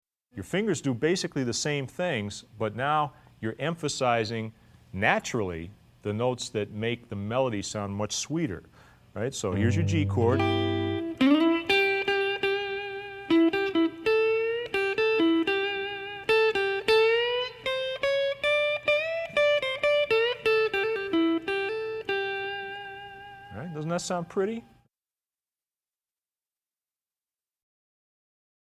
Voicing: Guitarr w/